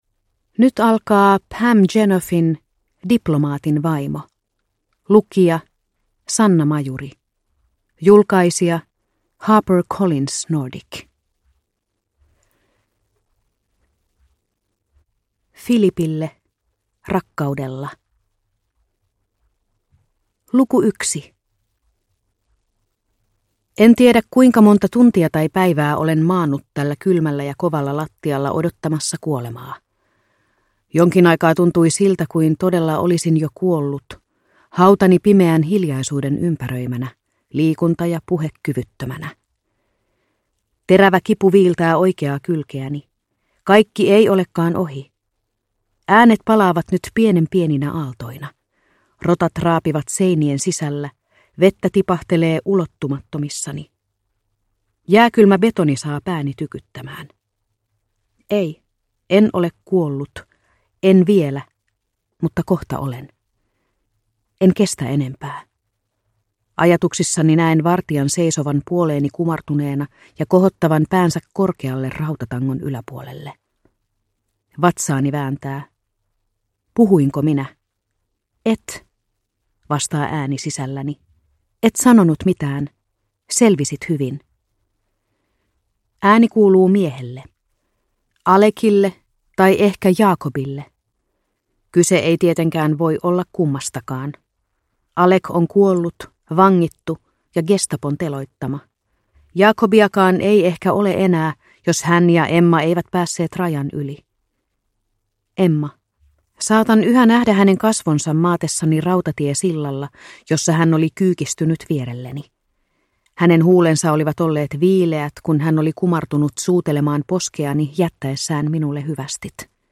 Diplomaatin vaimo – Ljudbok – Laddas ner